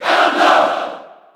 File:Ganondorf Cheer JP SSB4.ogg
Ganondorf_Cheer_JP_SSB4.ogg